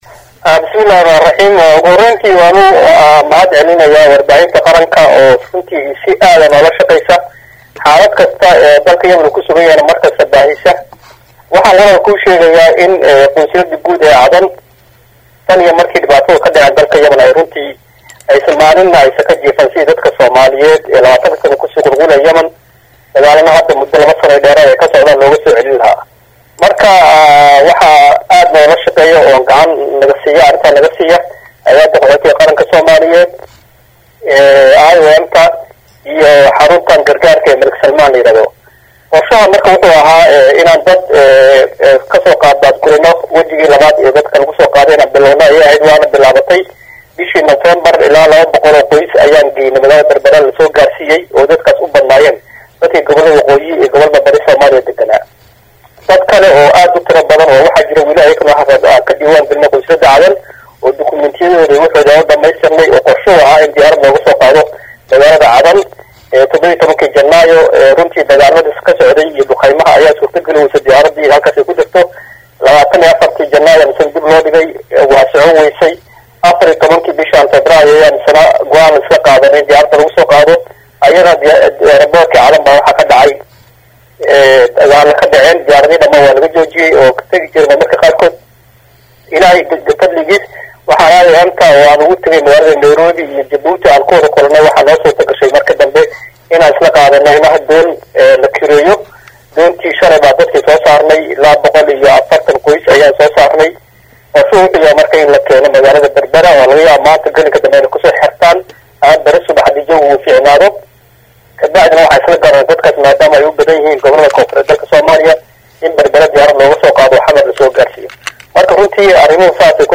Wareysi + Sawiro:Qunsulyida Soomaalida ee Yamen oo maanta qaxooti kale u fududeysay in dib loosoo celiyo.